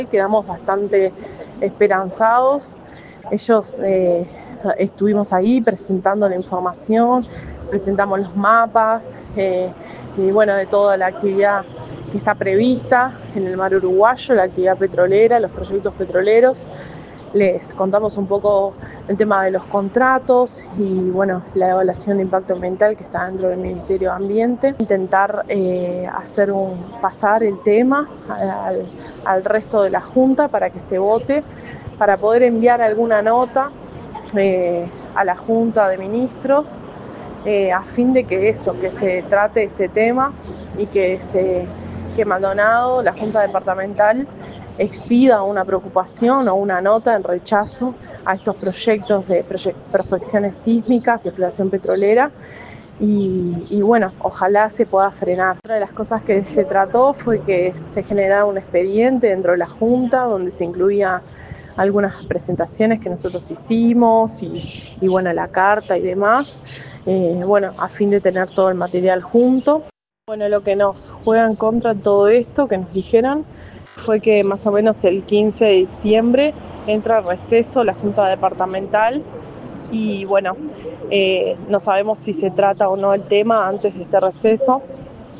le manifestó a Radio Uruguay, a la salida de la reunión, satisfacción por la receptividad de los ediles.